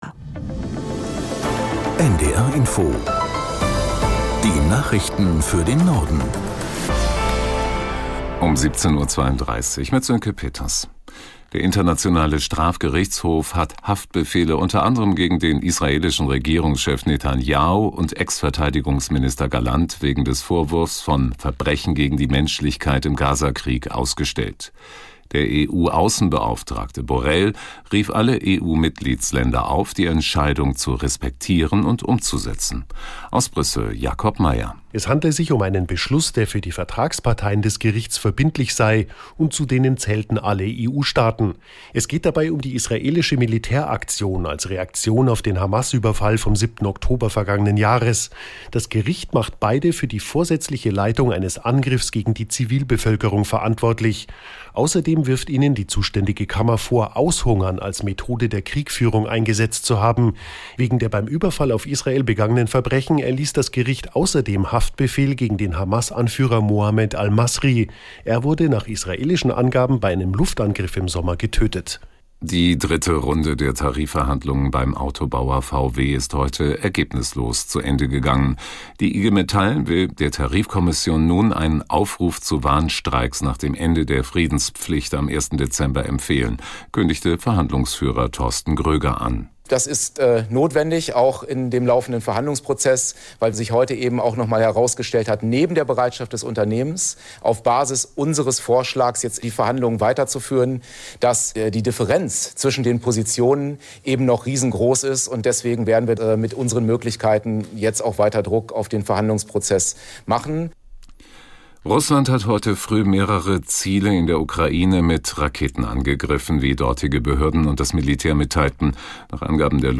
1 Nachrichten 5:02